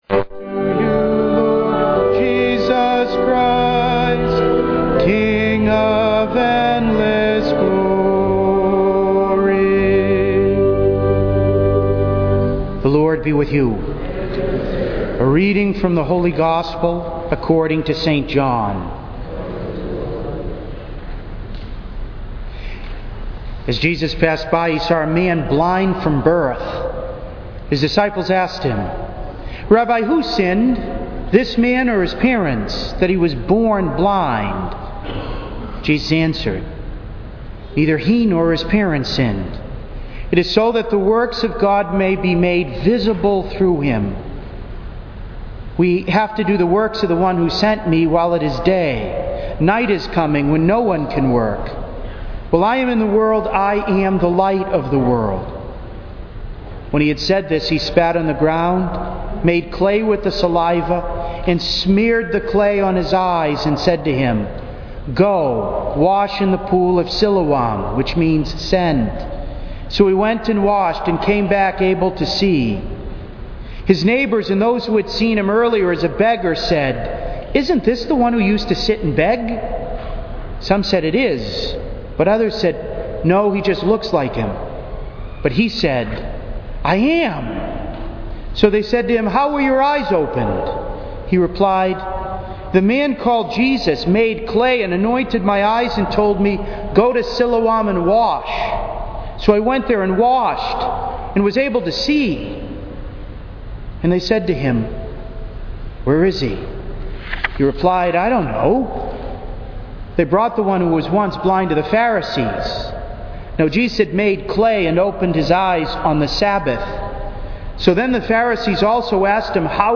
To listen to an audio recording of today’s Gospel and homily, please click below: